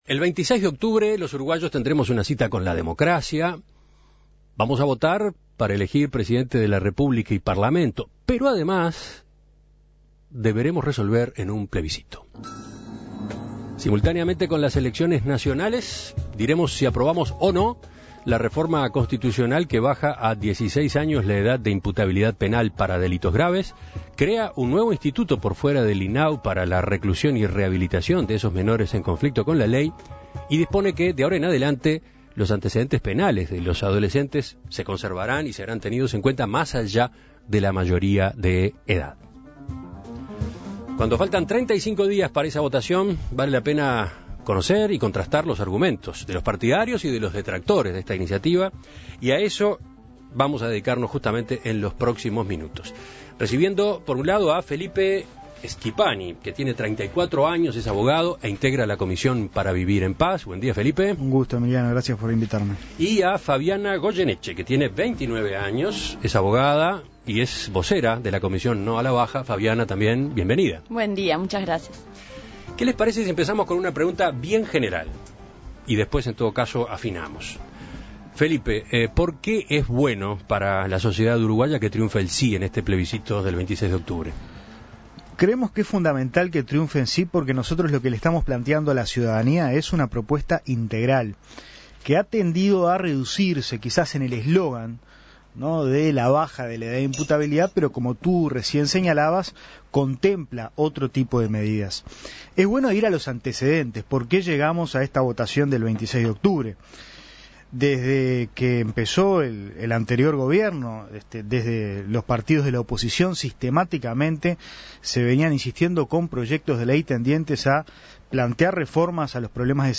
Debate En Perspectiva: Los argumentos a favor y en contra de la baja de la edad de imputabilidad penal a los 16 años